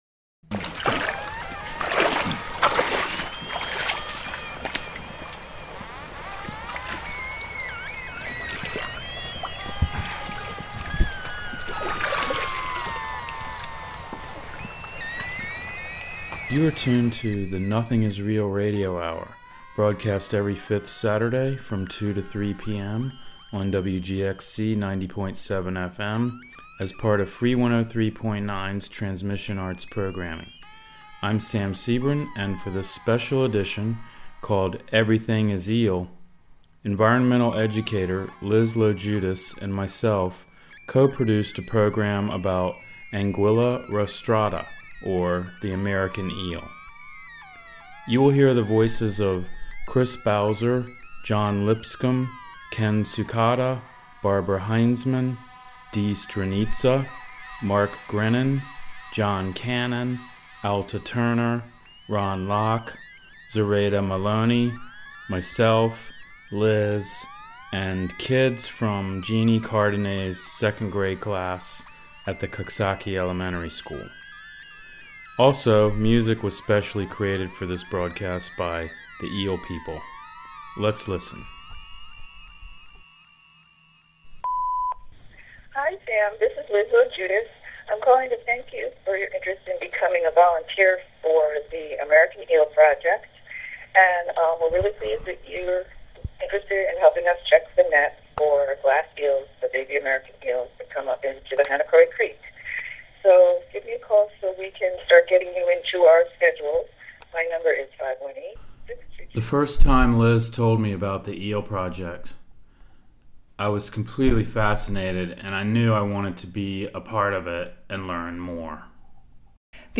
Together, they collected and edited audio from various eel experts and volunteers of all ages at various locations during the 2012 eel counting season. Tune in to hear more about this project, and this nearly endangered, enigmatic fish and its incredible journey. Plus, hear special sounds created for this broadcast by the Eel People.